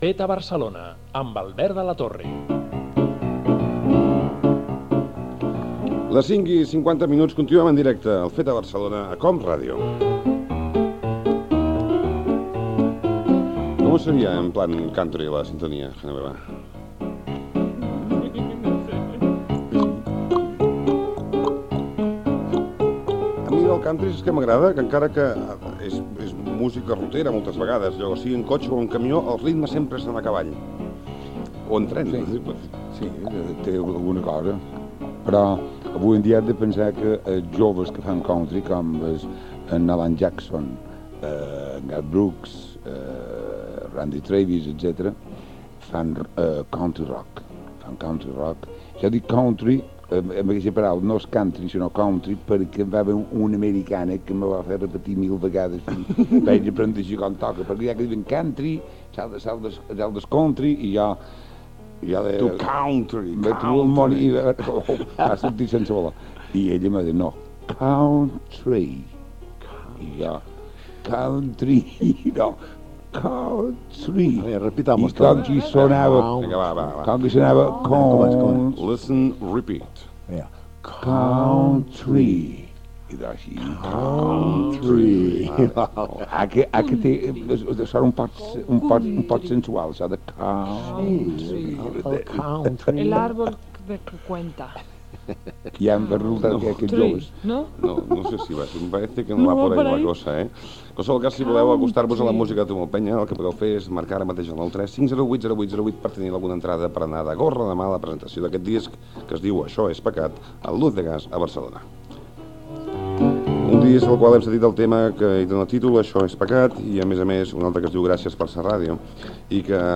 Indicatiu del programa, hora, variacions musicals de la sintonia i inici de l'entrevista al cantant Tomeu Penya
Entreteniment